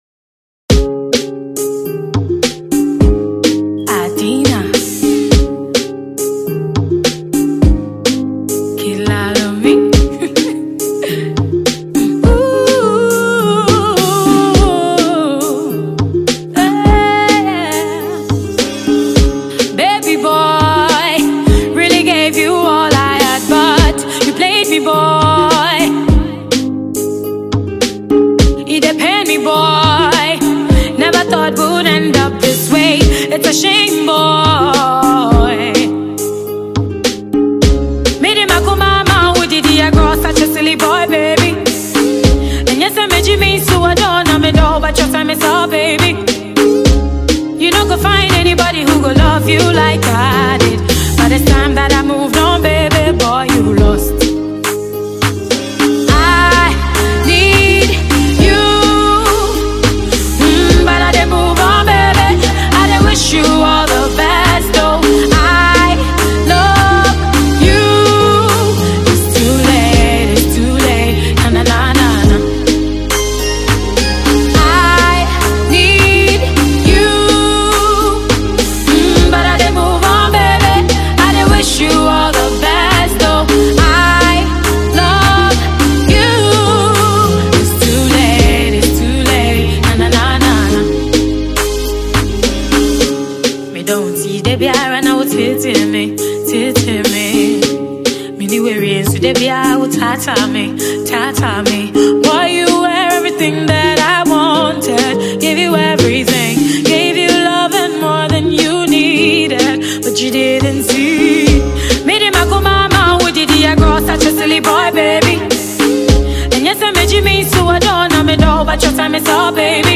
Ghanaian female singer